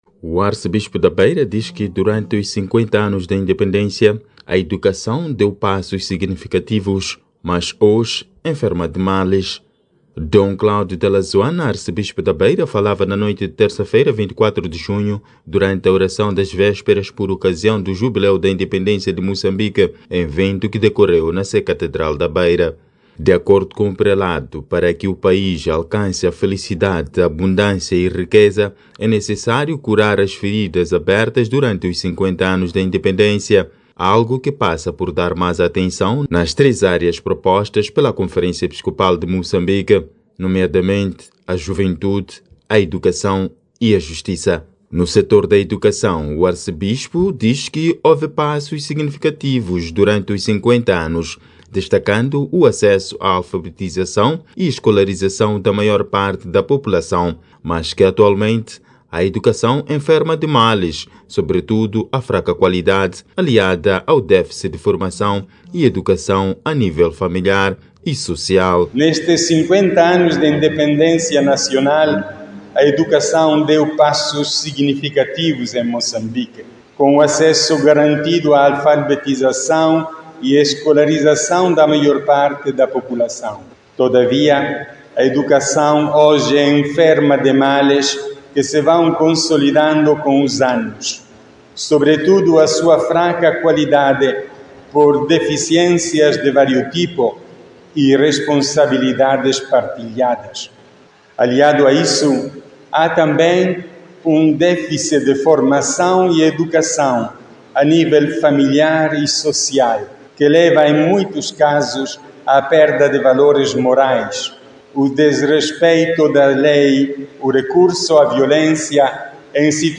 Dom Cláudio Dalla Zuanna, Arcebispo da Beira, falava na noite de Terça-feira, 24 de Junho, durante a oração das vésperas por ocasião do Jubileu da Independência de Moçambique, evento que decorreu na Sé Catedral da Beira.
Era o Arcebispo da Beira, Dom Cláudio Dalla Zuanna, e a sua reflexão nas vésperas da independência, evento que juntou na Sé Catedral da Beira, dirigentes e representantes de partidos políticos da província.